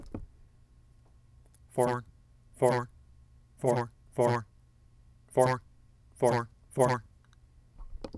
I have rested the headphones on the table, and have the mic of an external recorder next to the earcup.  The recorder mic picks up ambient (my direct voice) as well as the codec headphone output.  Playing the attached recording, you can hear the annoying echo experienced by the user (direct voice plus delayed voice through the codec).